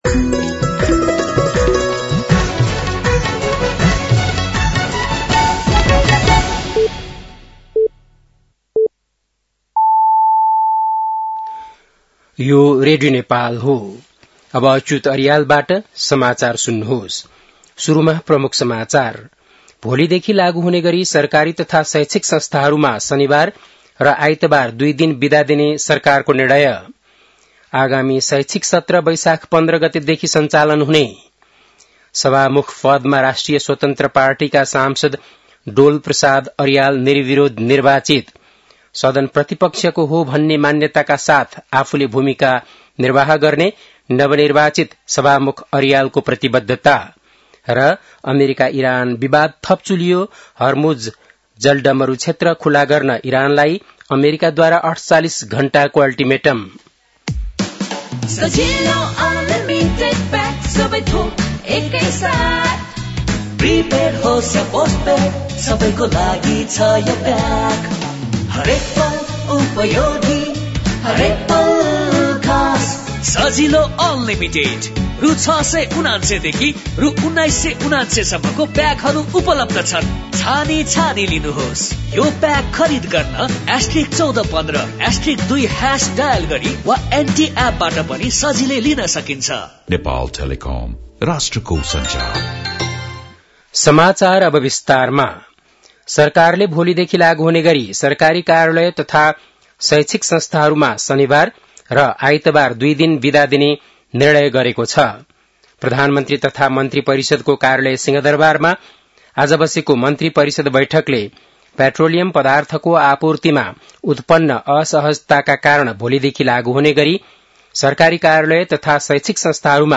बेलुकी ७ बजेको नेपाली समाचार : २२ चैत , २०८२
7.-pm-nepali-news.mp3